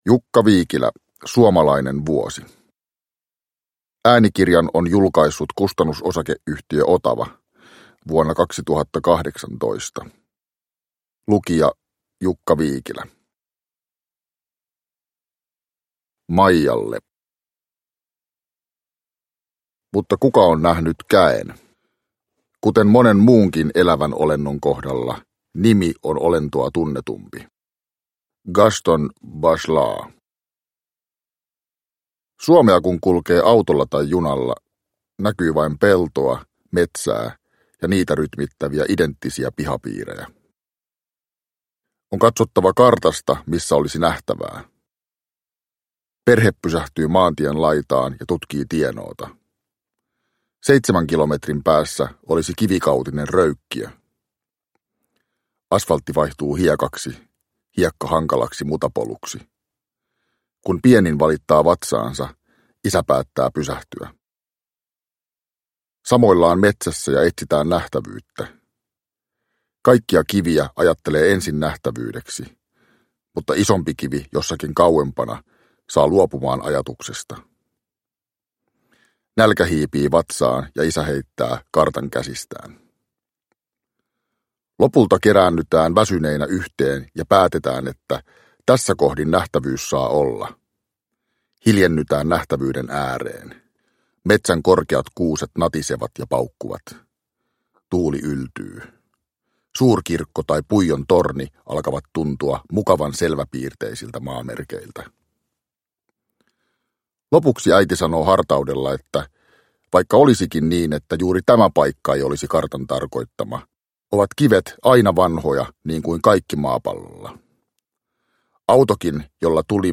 Suomalainen vuosi – Ljudbok
Uppläsare: Jukka Viikilä